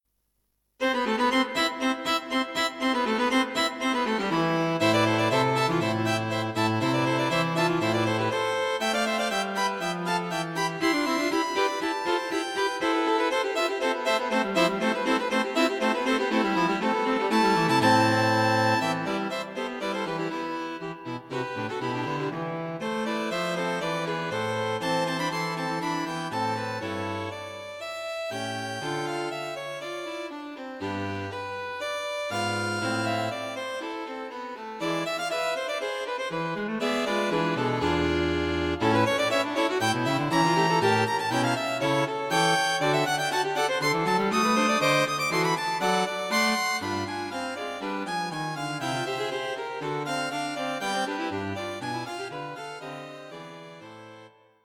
String Quartet for Concert performance
A fast and intricate piece for String Quartet.